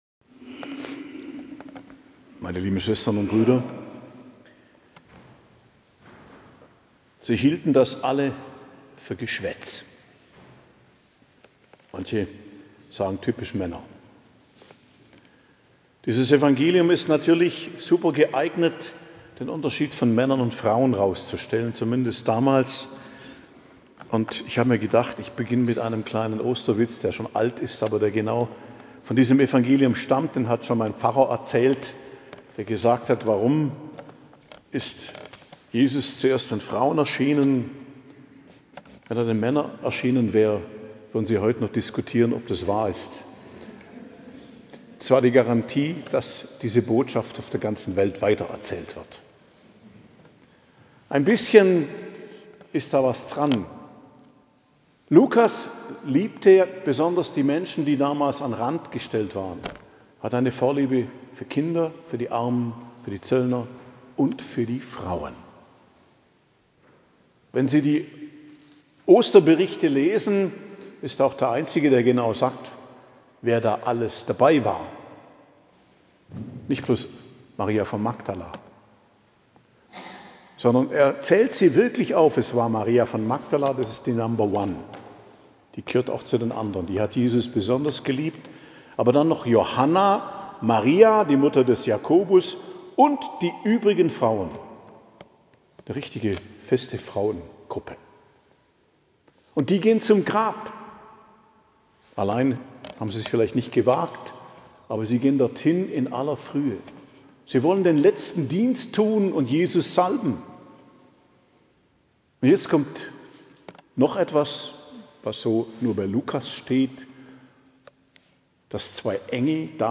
Predigt zur Feier der Osternacht, 19.04.2025 ~ Geistliches Zentrum Kloster Heiligkreuztal Podcast